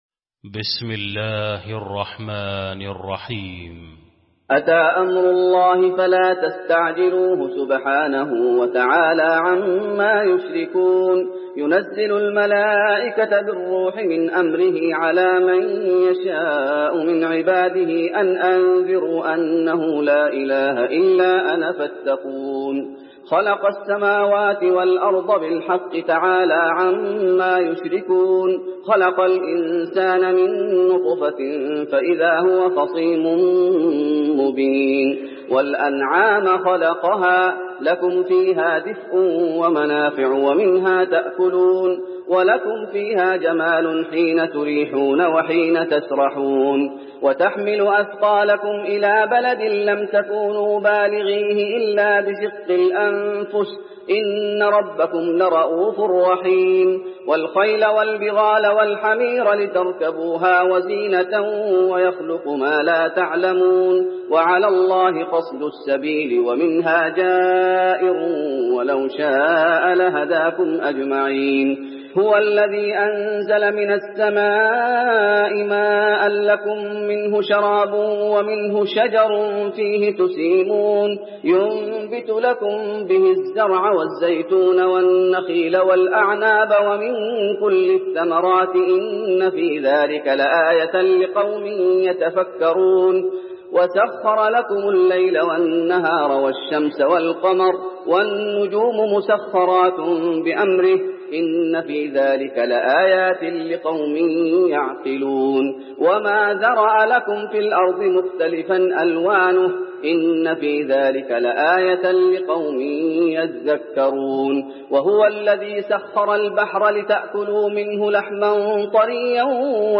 المكان: المسجد النبوي النحل